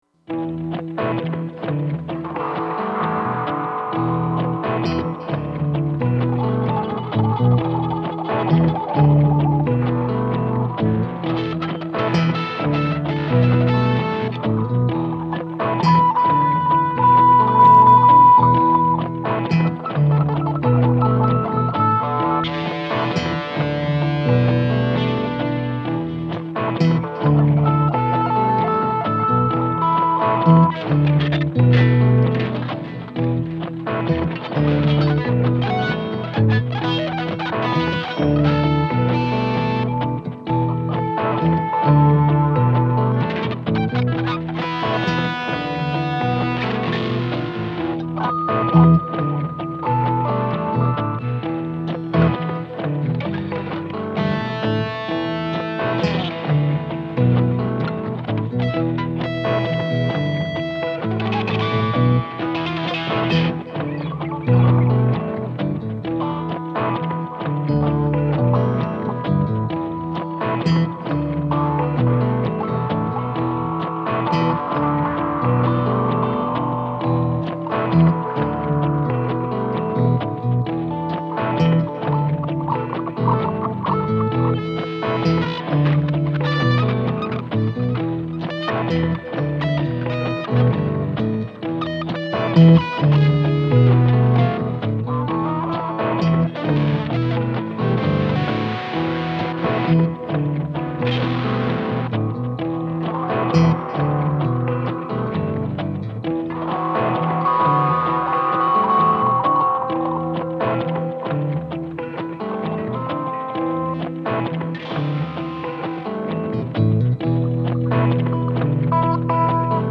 Das heutige Mitbringsel kleiner Fender-Exkursionen in die Höhlenwelt der Sound-Escapes brachte drei kleine Stücke ans Tageslicht, sie heissen:
Sound-escaping „In die Vollen“ gefällt mir am besten, so wunderbar verhuscht und krautig-treibend.